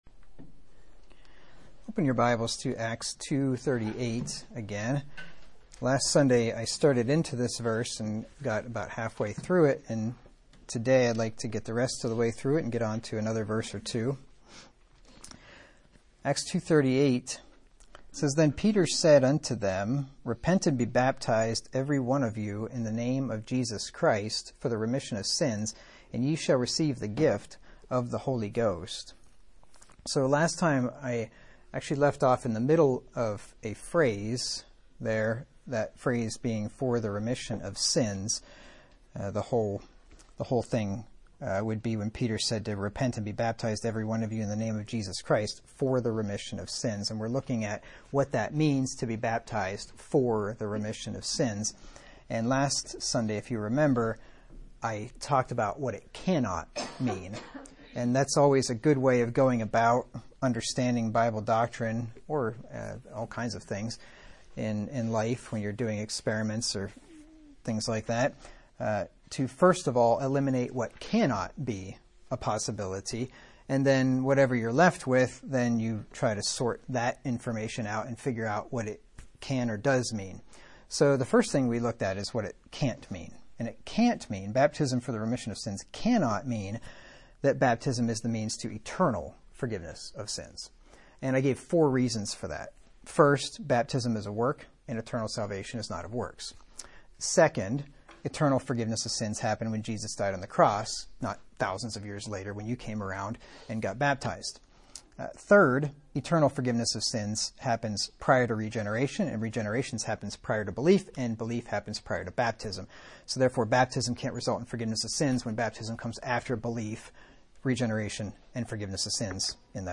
Sermons by 2026 | The Excelsior Springs Church